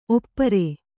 ♪ oppare